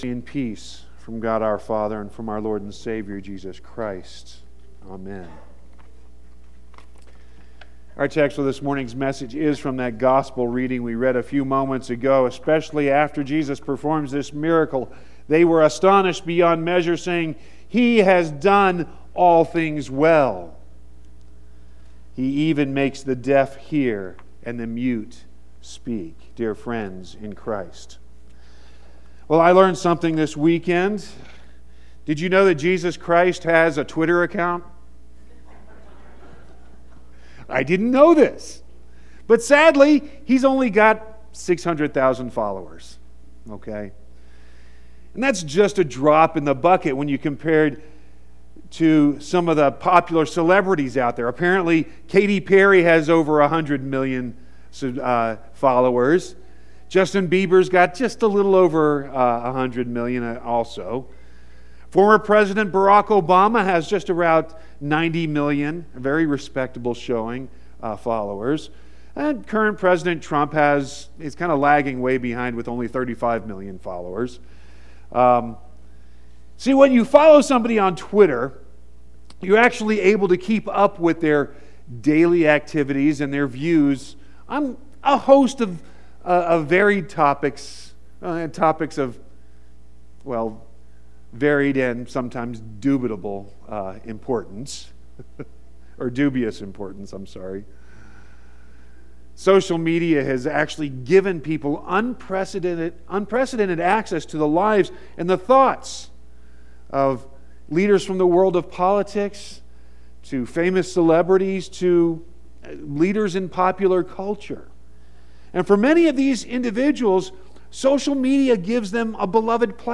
9-9-18-sermon.mp3